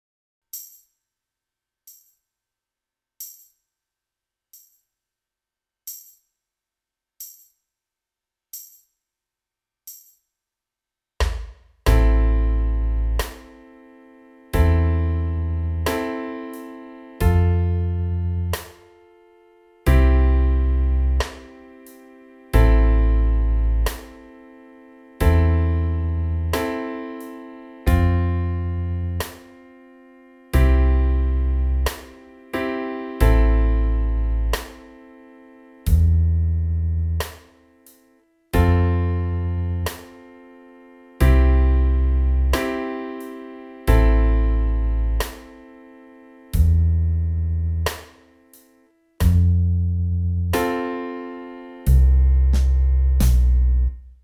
Soundbeispiel – Melodie & Band sowie Band alleine: